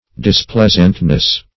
-- Dis*pleas"ant*ness , n. [Obs.]
displeasantness.mp3